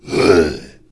spawners_mobs_uruk_hai_hit.3.ogg